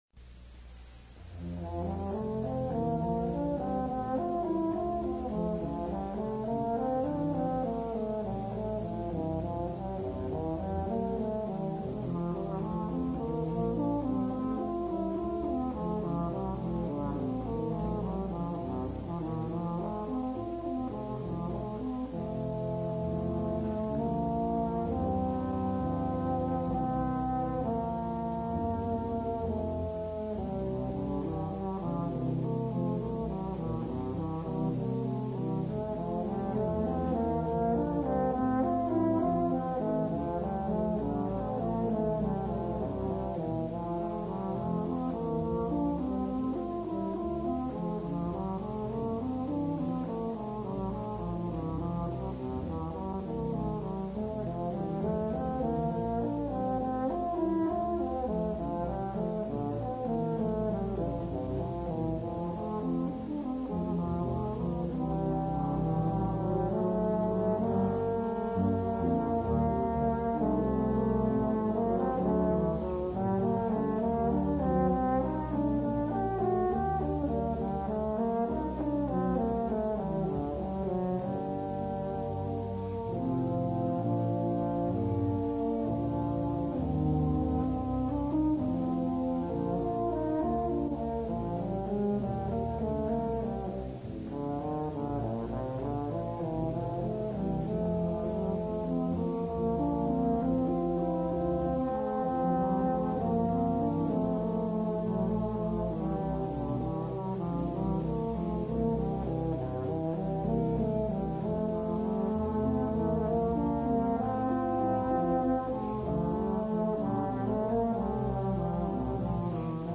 For Tuba Quartet (EETT)